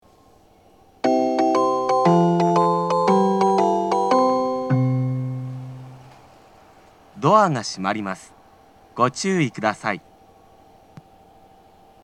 発車メロディー
スイッチを一度扱えばフルコーラス鳴ります。
Rolandスピーカーは音質が良いです。